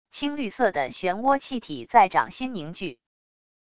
青绿色的漩涡气体在掌心凝聚wav音频